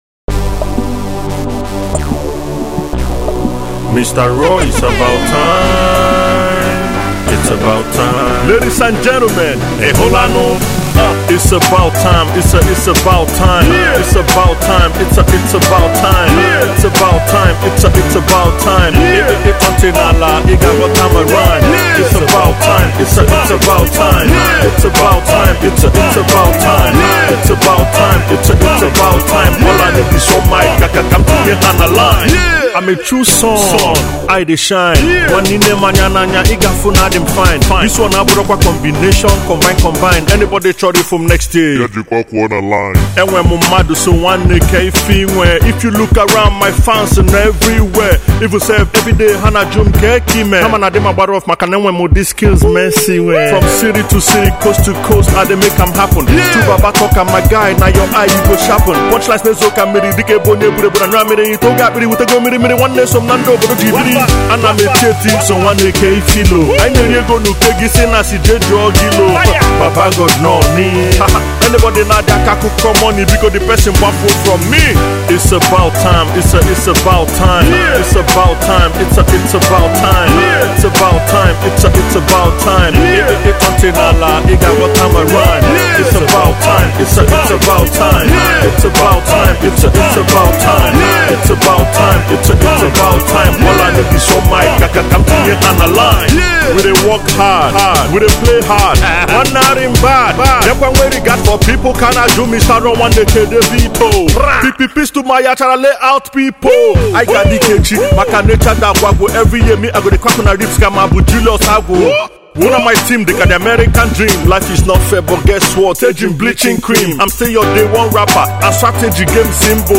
Igbo Music, indigenous Hip-Hop
Igbo Rap
Rap single
Though the hook is a bit lazy